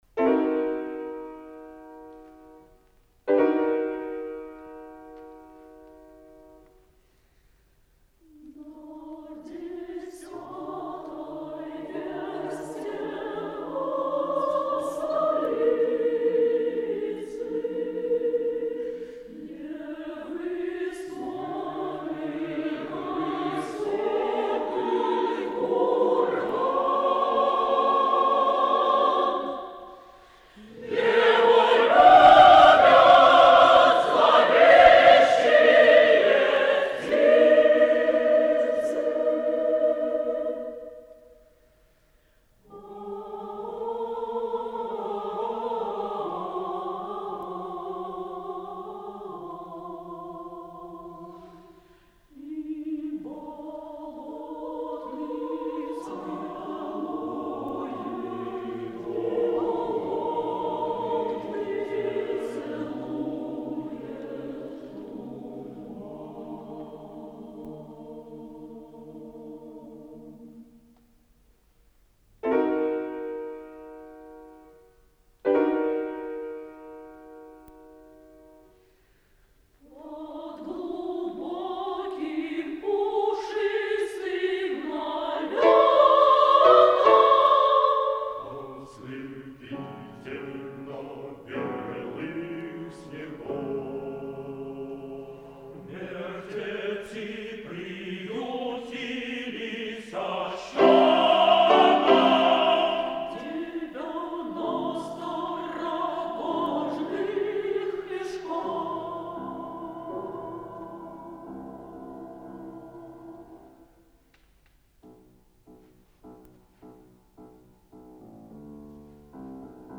Печальная песня